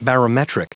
Prononciation du mot barometric en anglais (fichier audio)
Prononciation du mot : barometric